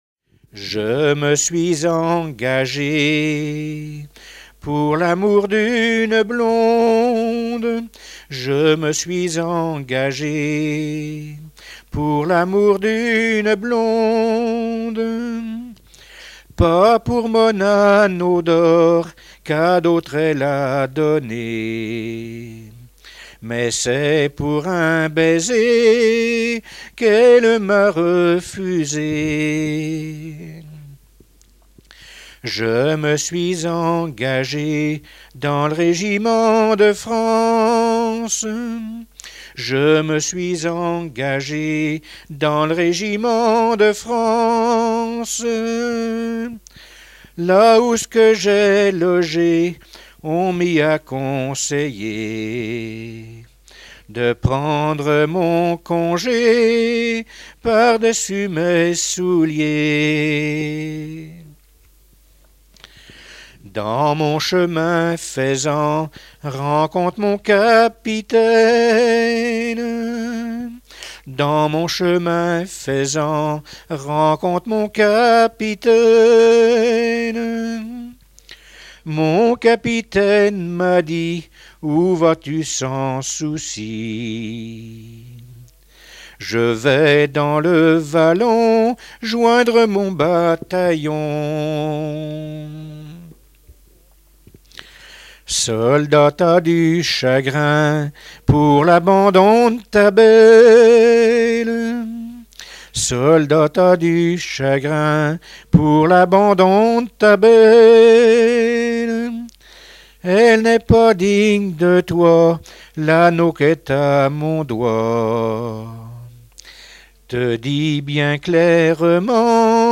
Treize-Septiers
Genre strophique